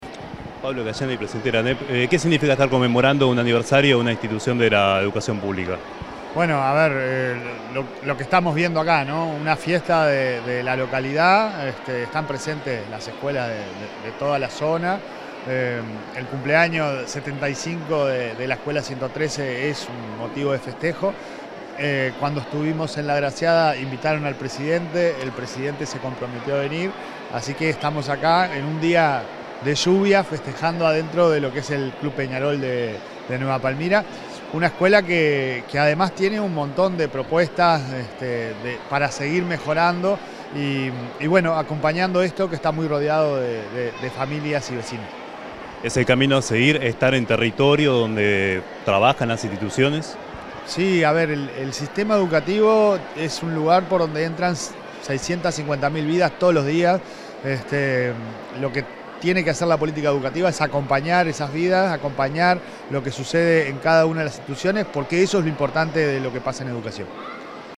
Declaraciones del presidente de ANEP, Pablo Caggiani
El presidente de la Administración Nacional de Educación Pública (ANEP), Pablo Caggiani, realizó declaraciones luego de participar en el 75.°
caggiani_prensa.mp3